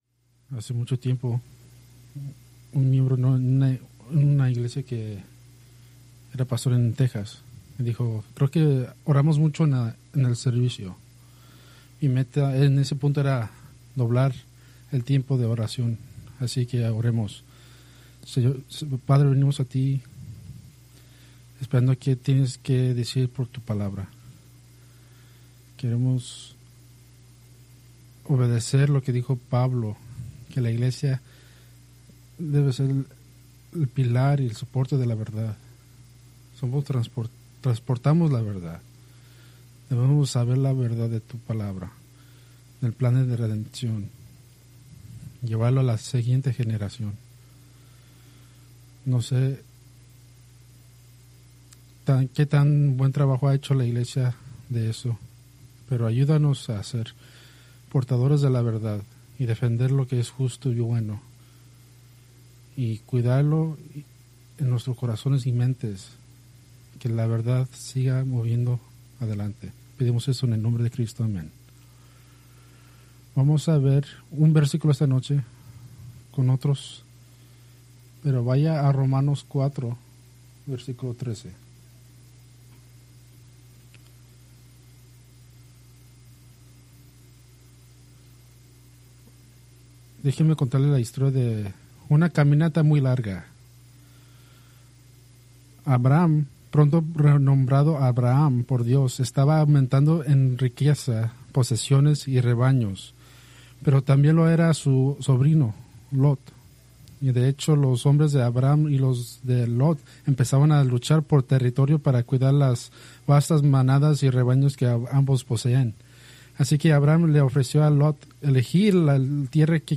Preached February 8, 2026 from Escrituras seleccionadas